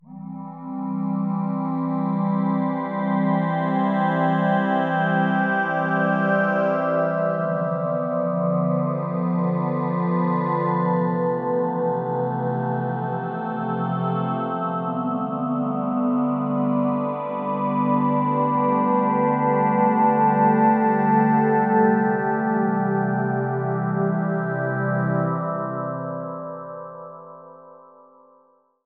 Krystal Wind on Synth Pad